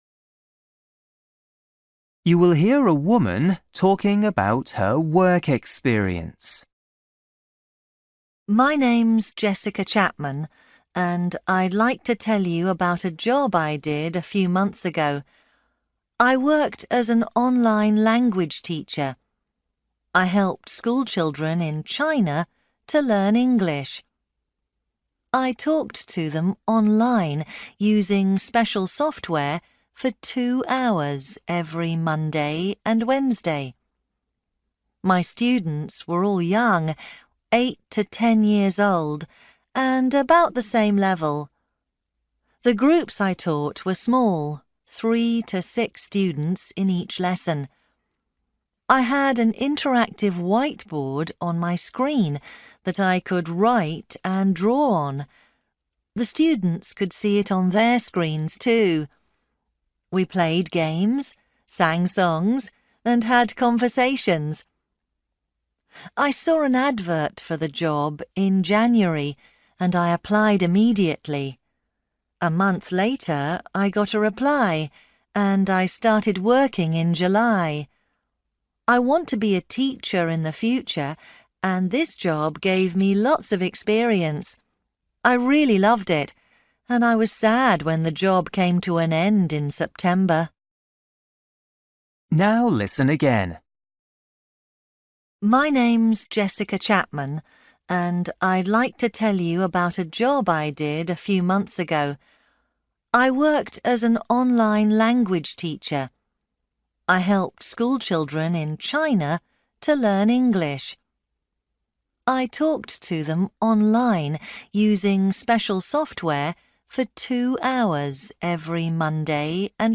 You will hear a woman talking about her work experience.